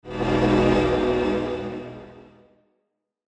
ピシュウ